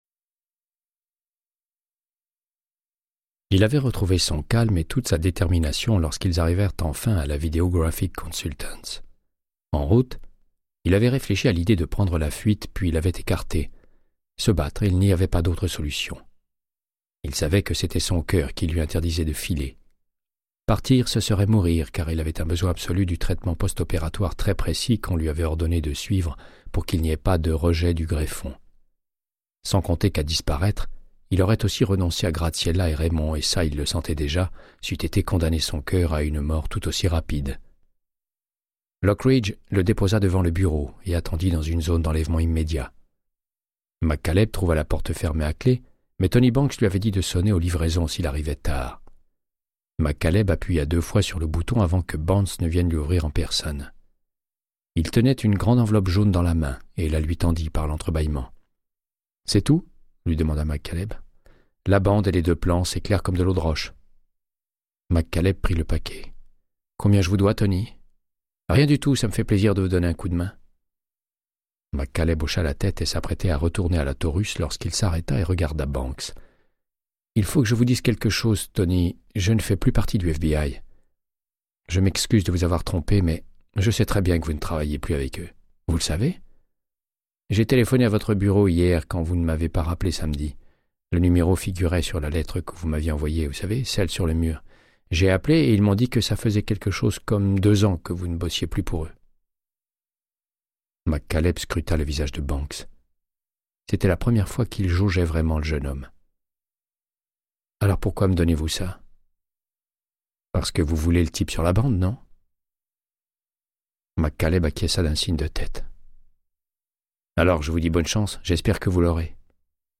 Audiobook = Créance de sang, de Michael Connelly - 125